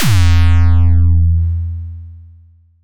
Kick Bass 1.wav